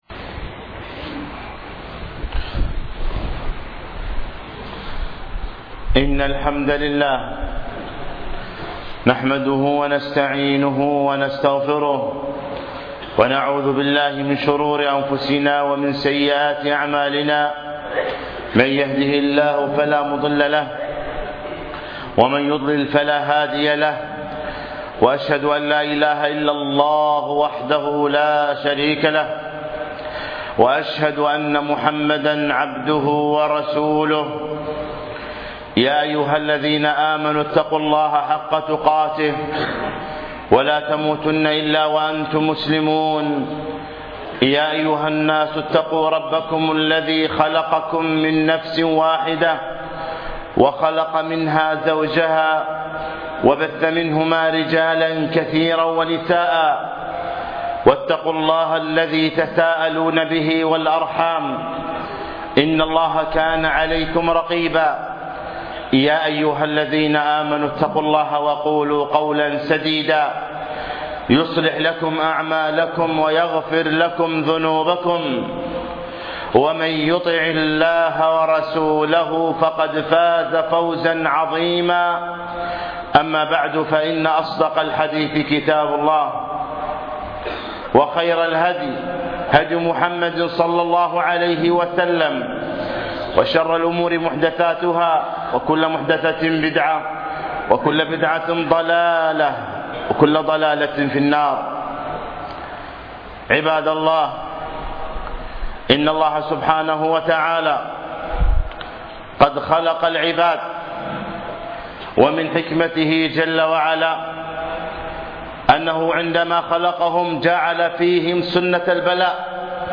خطبة بتاريخ 11 ربيع الأول 1436 الموافق 2 1 2015